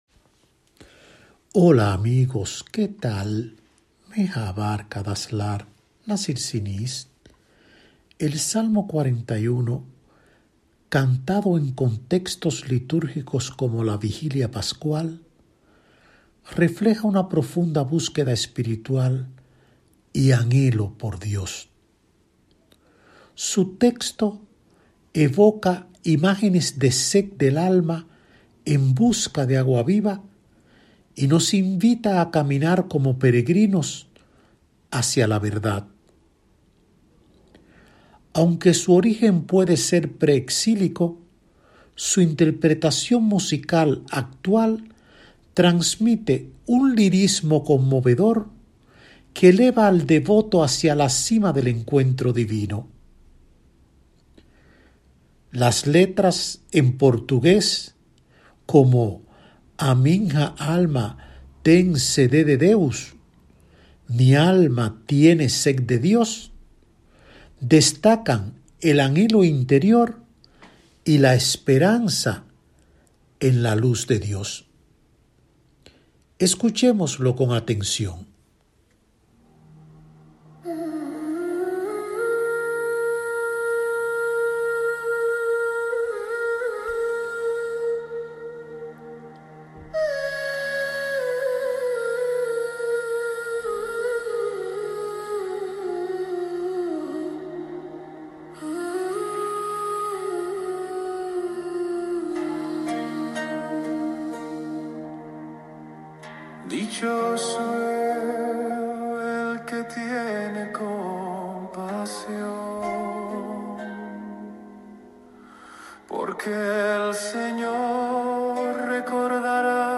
Aunque su origen puede ser pre-exílico, su interpretación musical actual transmite un lirismo conmovedor que eleva al devoto hacia la cima del encuentro divino.